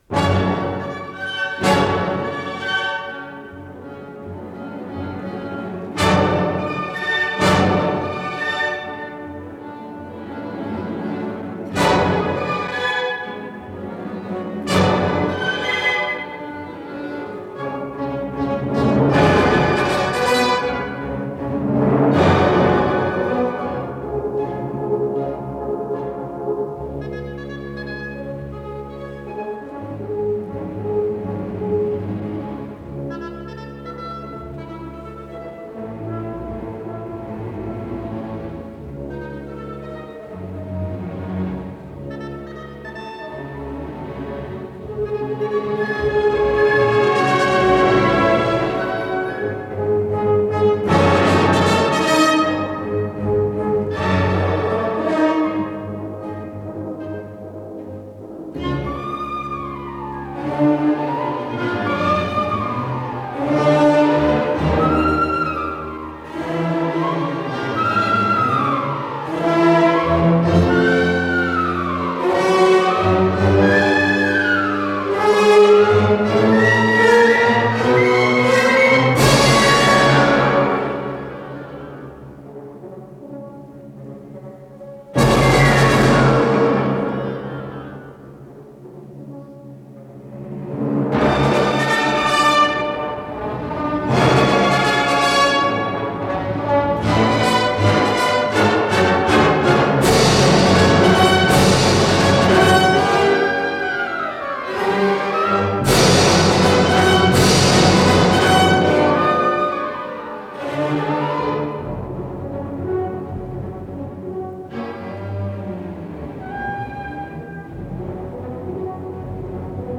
с профессиональной магнитной ленты
ИсполнителиБольшой симфонический оркестр Всесоюзного радио и Центрального телевидения
Художественный руководитель и дирижёр - Геннадий Рождественский
ВариантДубль моно